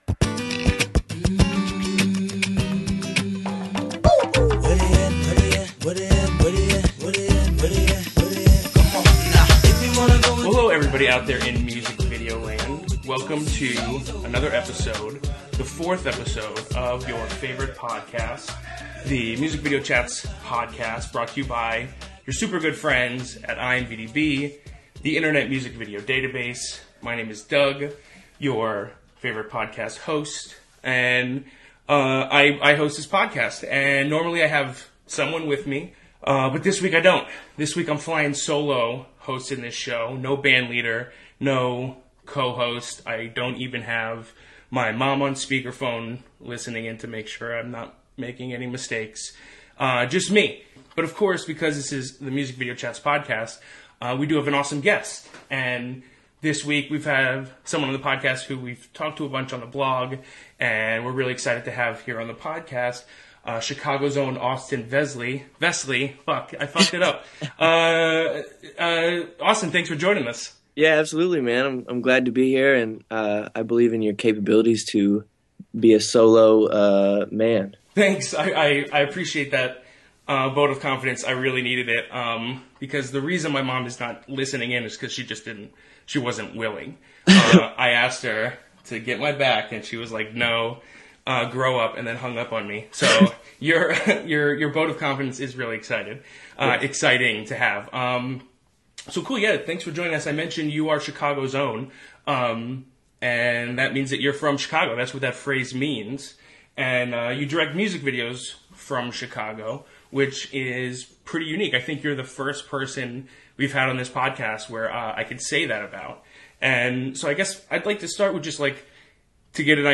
Conversations with people making music videos.